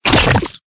CHQ_FACT_paint_splash.ogg